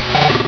Cri de Lanturn dans Pokémon Rubis et Saphir.